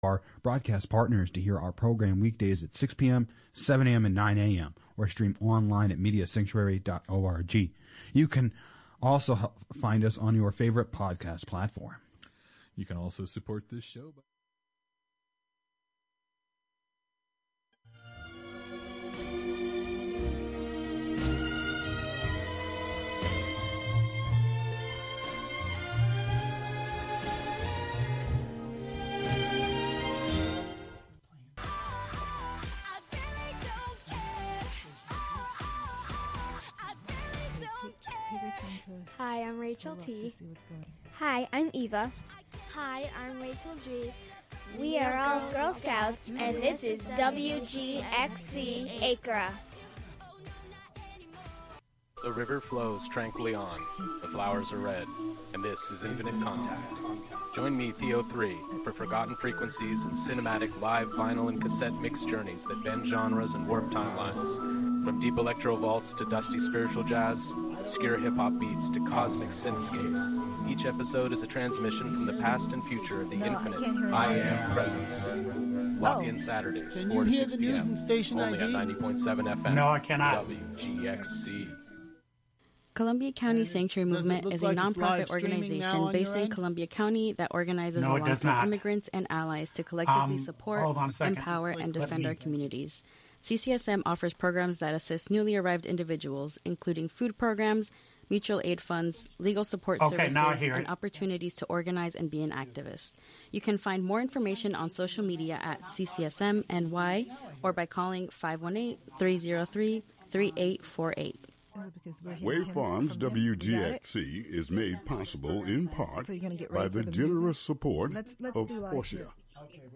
THEME: Freedom Under Fire: Sharing What We Are Thankful for in Rhythm and Verse In this episode, treat yourself to jazz that jumps and poetry that propels vivid images into the minds of those who listen.
Throughout the broadcast, expect poetry, jazz, and conversation that speak to resilience, artistry, and the ongoing fight for understanding. The TALK Term for the night, “Wokeness,” will challenge listeners to consider how awareness itself has become a political act—and what it really means to stay awake in times like these.